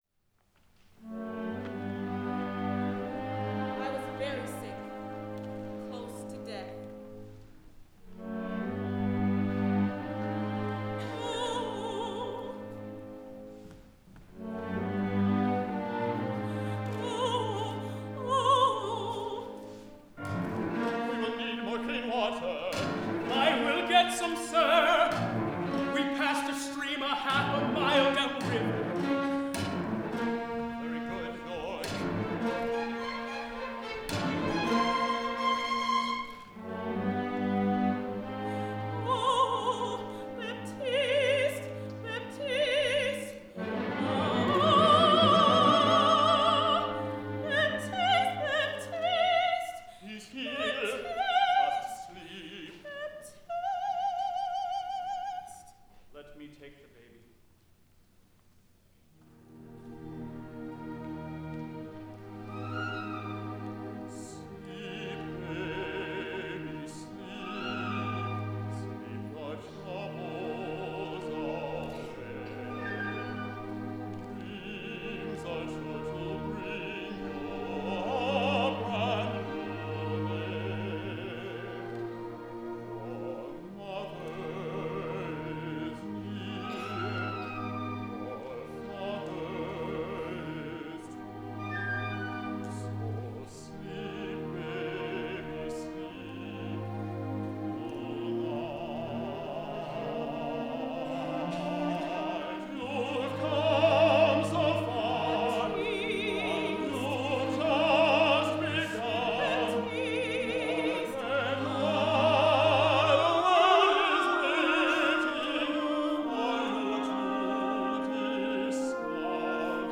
Show-Me Opera
Recording, musical
Lewis and Clark expedition, operatic performance, musical tribute
These recordings are excerpts from Corps of Discovery: A Musical Journey, a three-act opera by Michael Ching and Hugh Moffatt, which commemorates the expedition of Meriwether Lewis and William Clark and celebrates the bicentennial of their journey. The pieces were performed by the Show-Me Opera of the University of Missouri's School of Music in January 2003 at the University of Virginia.